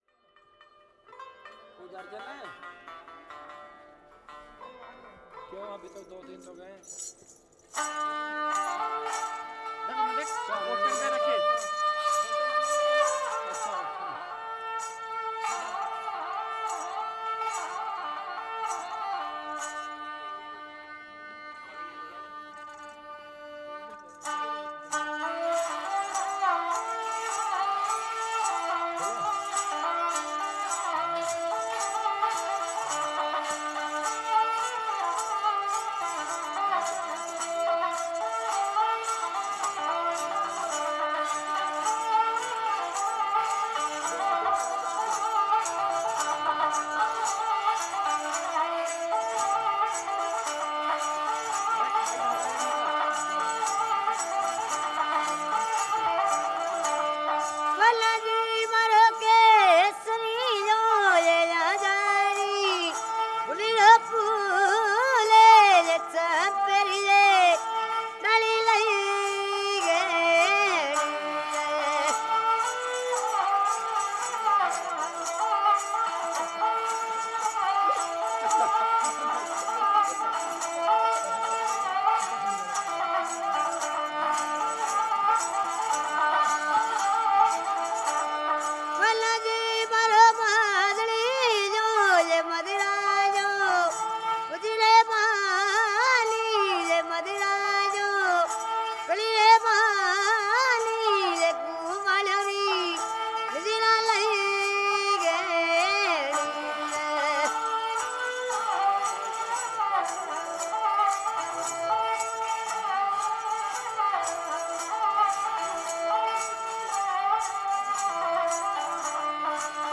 Playing the ravanahatha.
Passing through another large archway I emerge in a clearing to the sound of a man singing and playing an unfamiliar stringed instrument.
Playing as I record.
The sound of this instrument, the ravanahatha, is so unique. The way it resonates almost sounds like it is being played from inside a tin can. Then, as the player strokes the bow back and forth, the bells attached to the top of it shake and jingle in time with the song.
Although at the time the crowd that gathered around while I was recording were quite annoying, listening back now I don’t mind the sound of them chattering away; it sets the scene quite nicely. I also quite like the sound of the radio that goes off from the left near the start of the piece; it was almost perfectly timed to fit in with the song.
The focus between the ravanahatha and the vocals shift quite nicely and the sound of everything shines through beautifully.